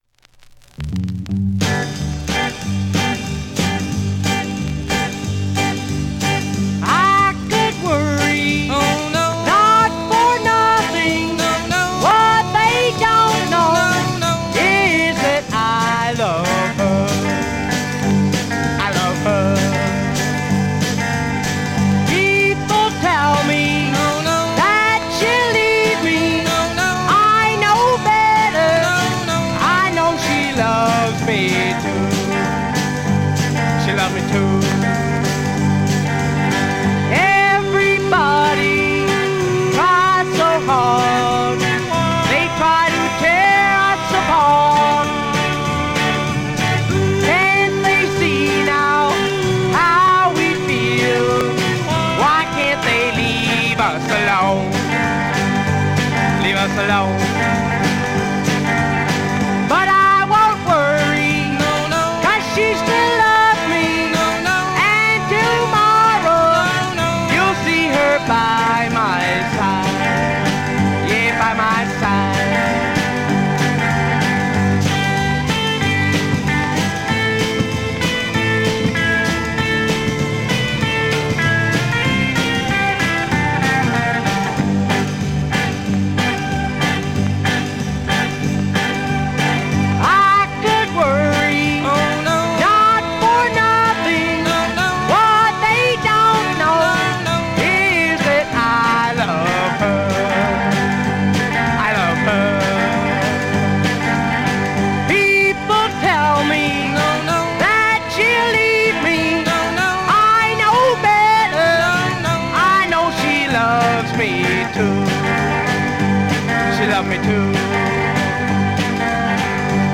drums
bass
organ
bouncy